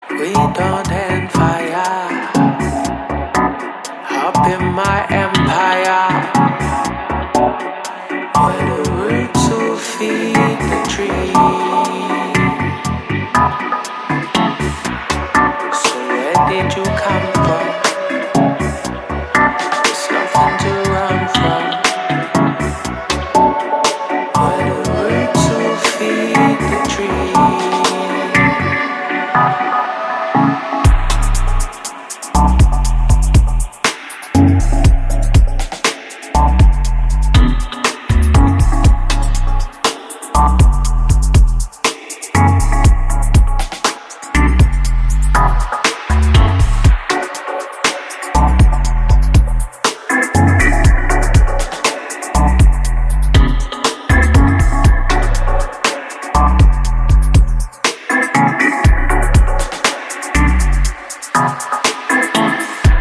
シロップのように甘くヘヴィなローエンド、郷愁に満ちたメロディカ、精霊のように漂うヴォーカル